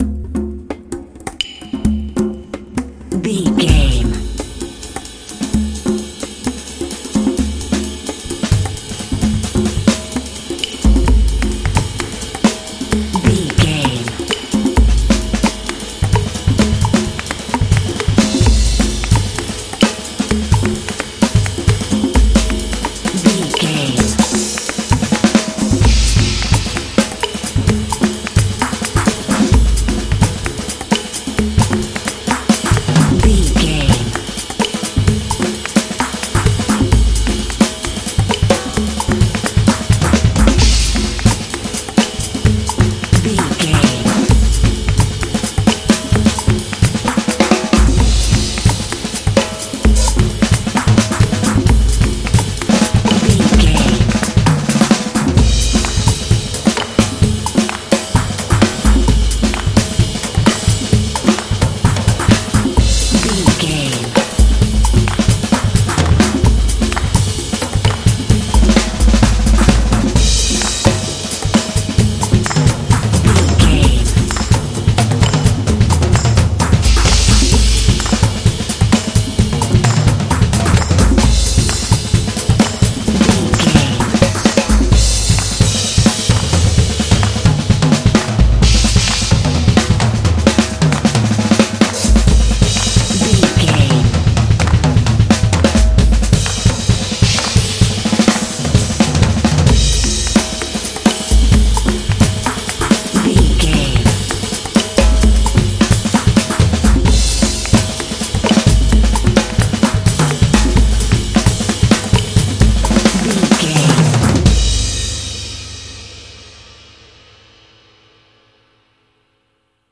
Atonal
groovy
percussion
jazz drums